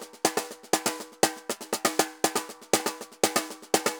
Tambor_Merengue 120_2.wav